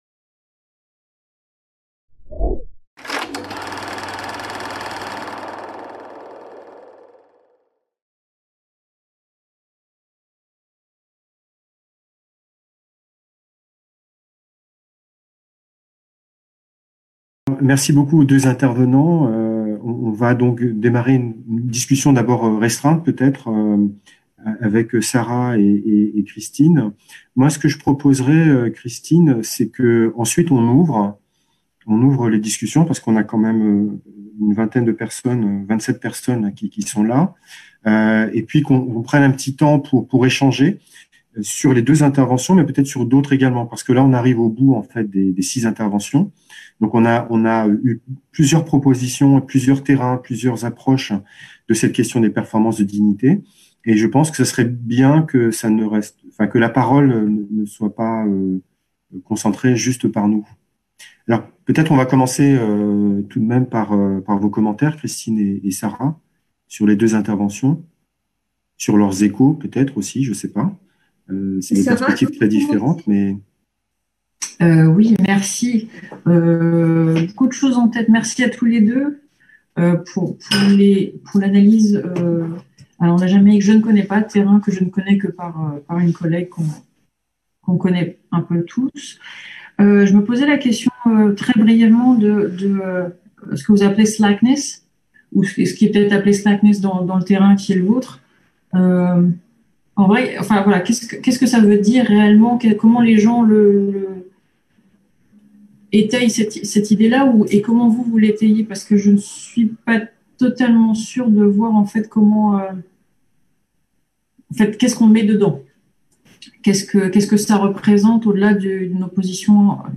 Corps et performances de dignité en contexte (post-)colonial. Amériques, Afrique, Océan indien - Discussion 3 | Canal U